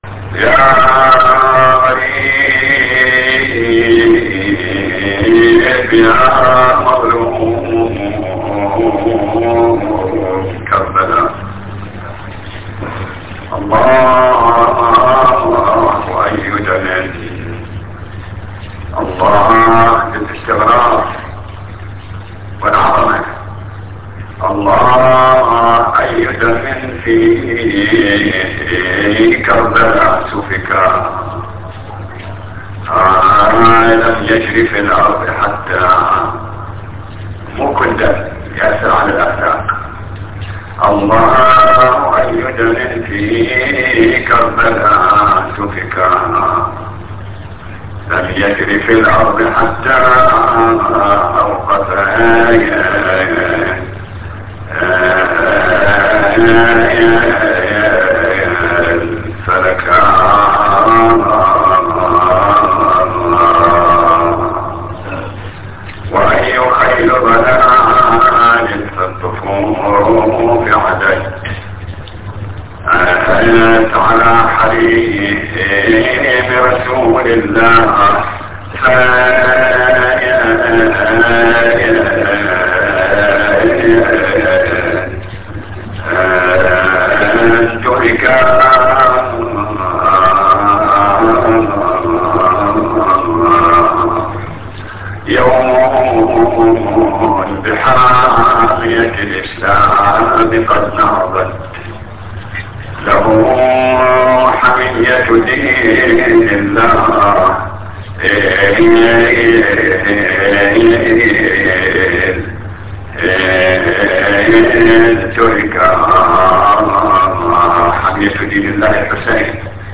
نواعي حسينية 6